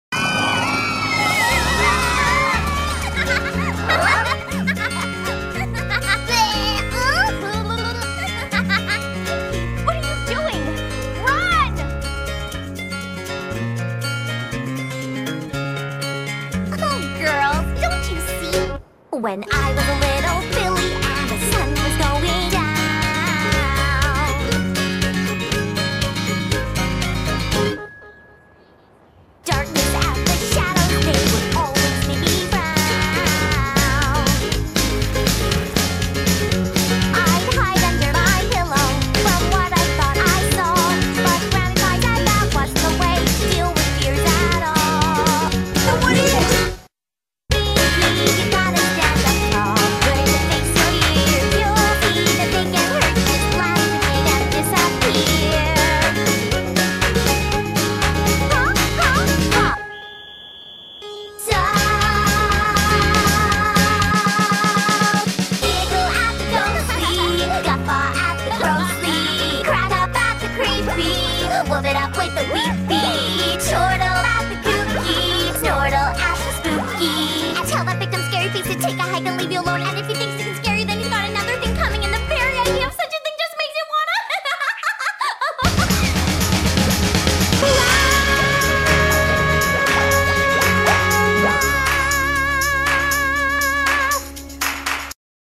Nothing added to the original tracks.